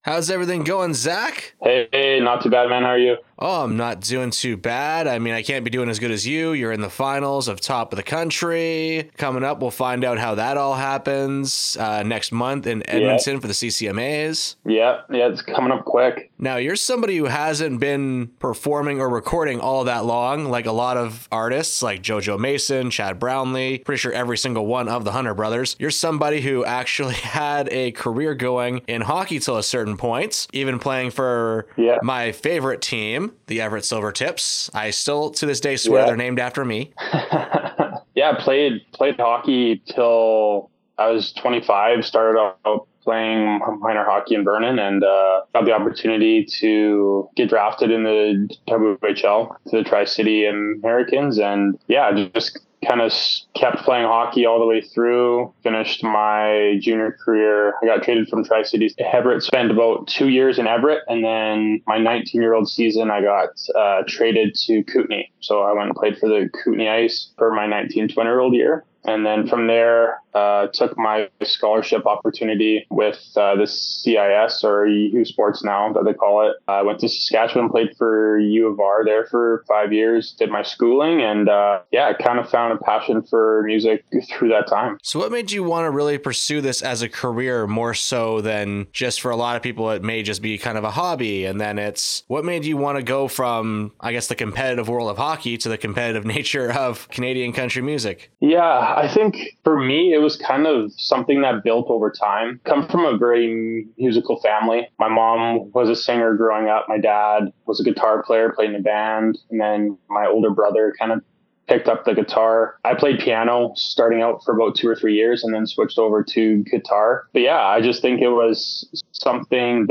Rising Stars Headliner Interview